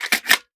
12ga Pump Shotgun - Load Shells 006.wav